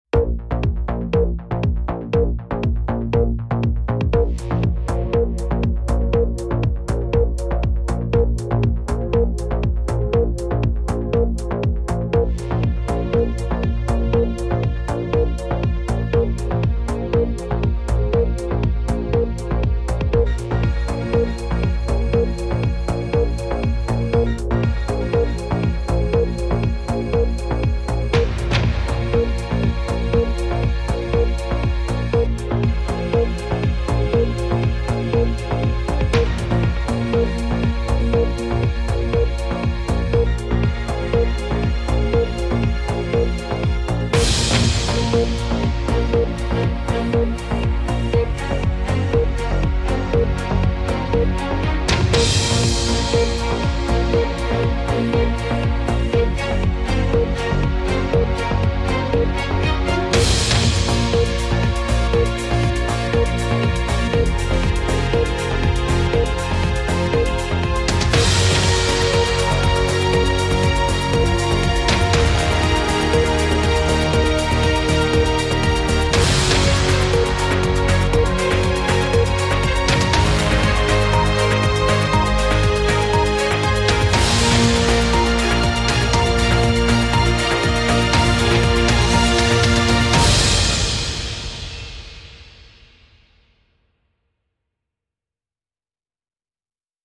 سبک موسیقی (Genre) تم, موسیقی متن